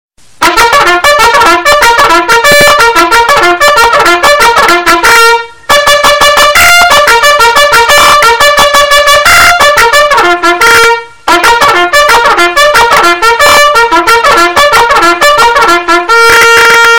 Categoria Allarmi